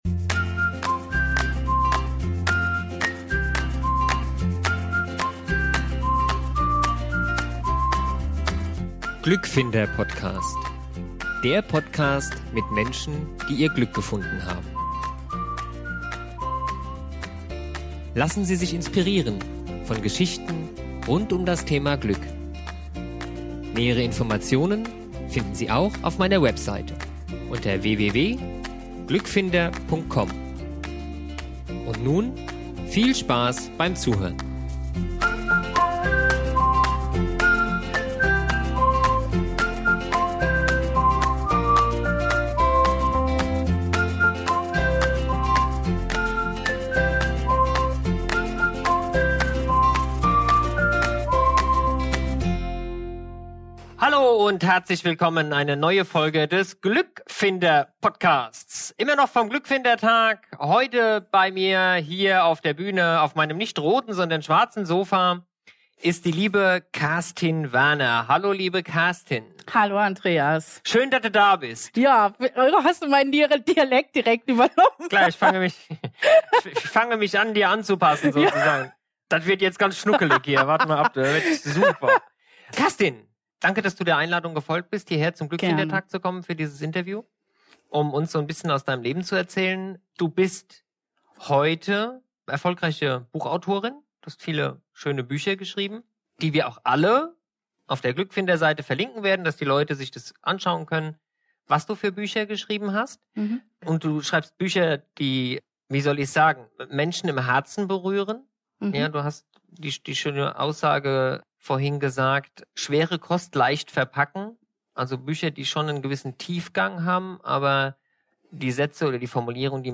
Ein wunderschönes Interview, mit einer wahren Frohnatur, bei dessen Produktion wir gemeinsam viel gelacht haben.